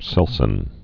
(sĕlsĭn)